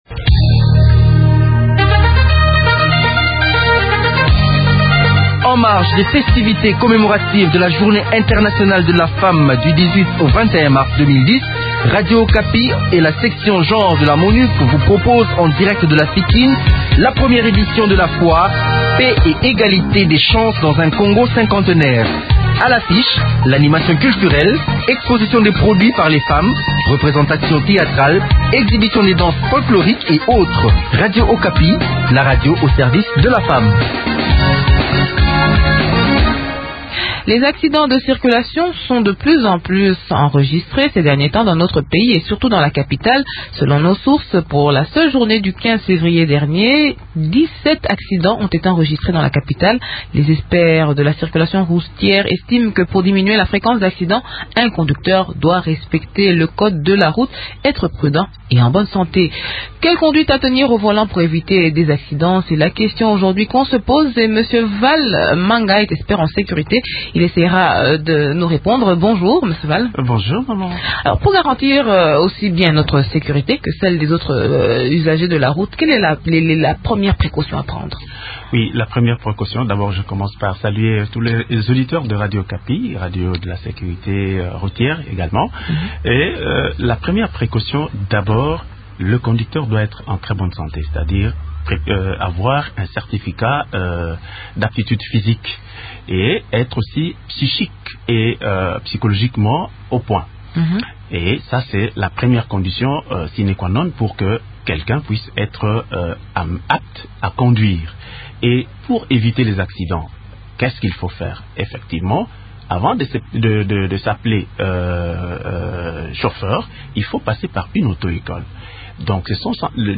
expert en sécurité routière.